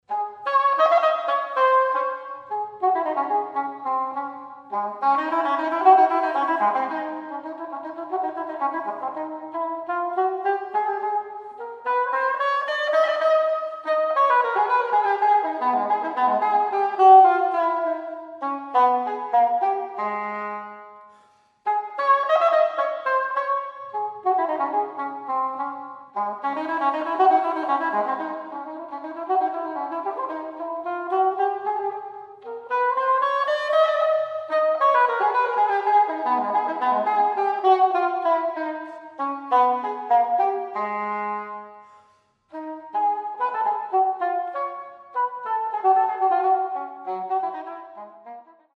oboe da caccia